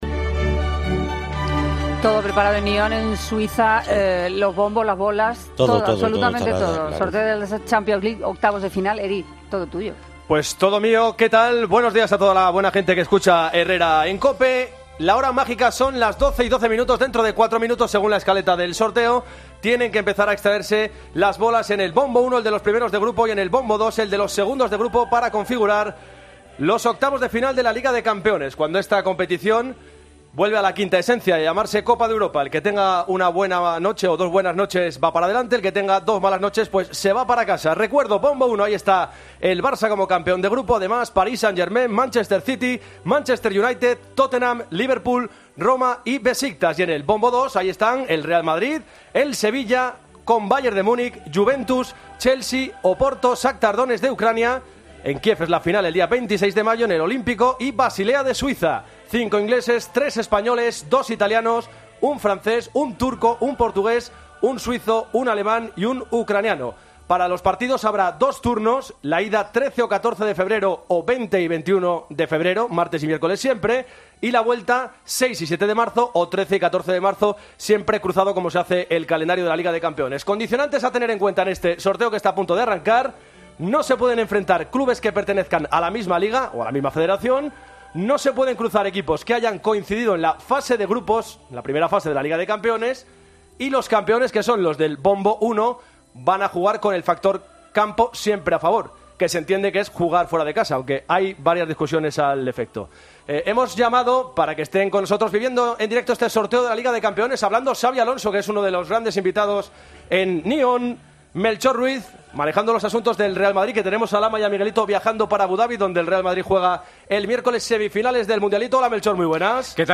Así se vivió en COPE el sorteo de los octavos de final de la Champions